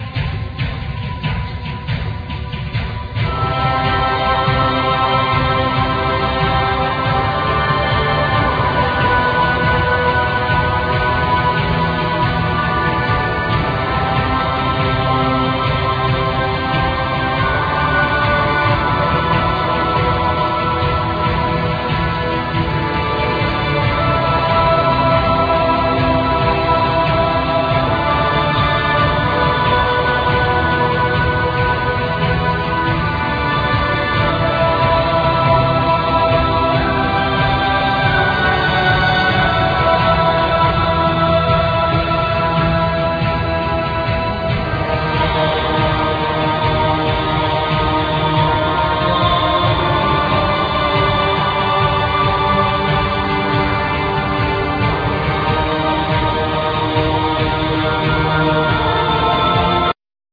Backing vocals,Dulcimer,Drums,Programming